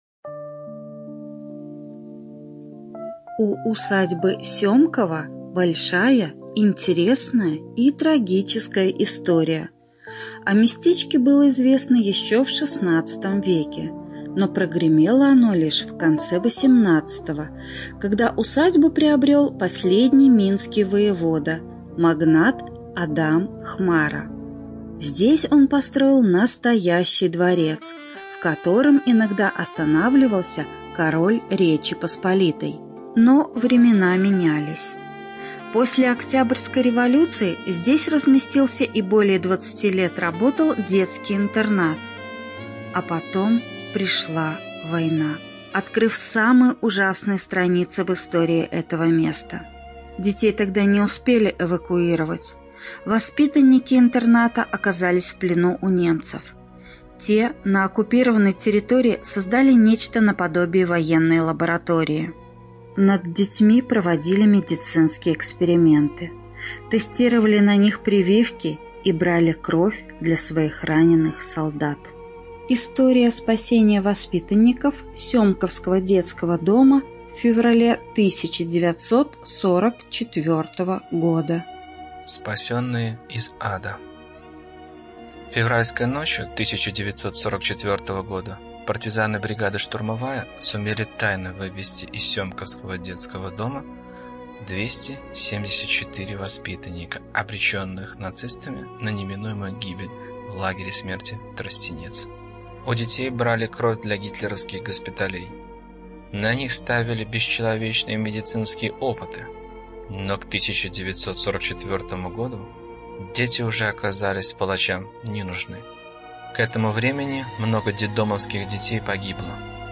Аудиодескрипция